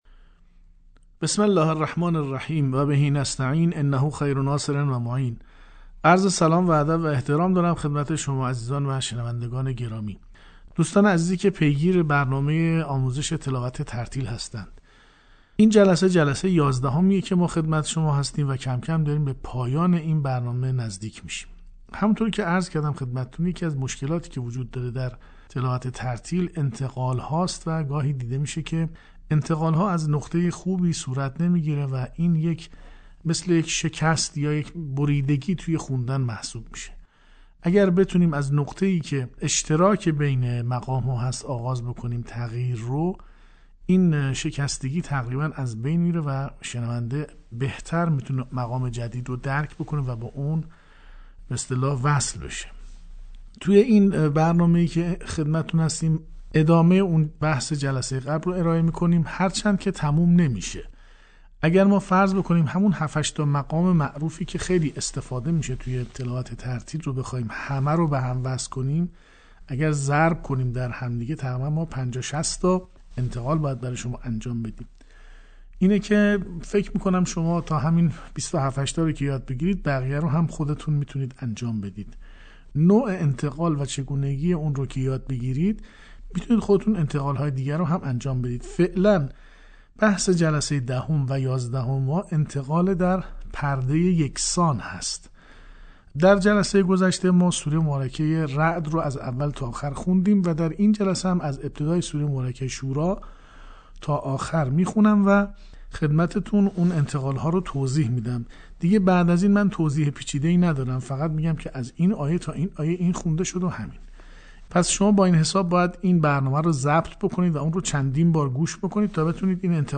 در ادامه صوت قسمت یازدهم آموزش ترتیل قرآن را می‌شنویم.